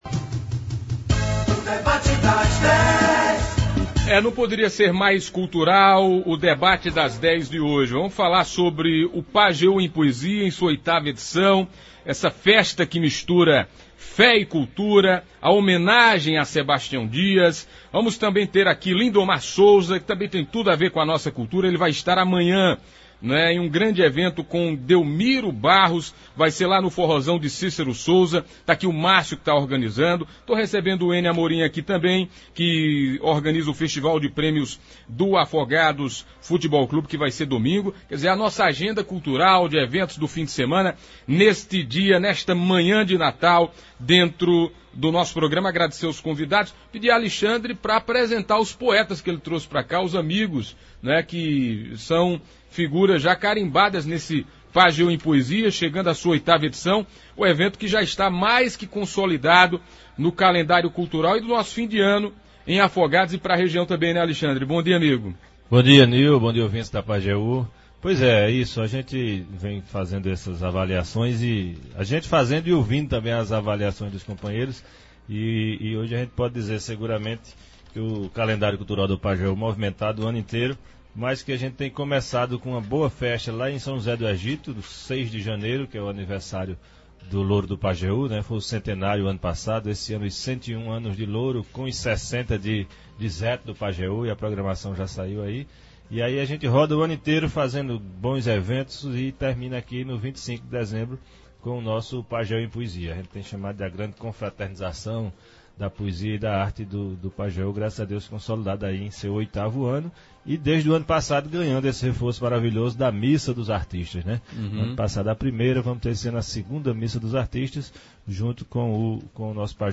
Eles falaram sobre a organização e expectativa dos eventos. Também teve muita música boa e poesia da melhor qualidade.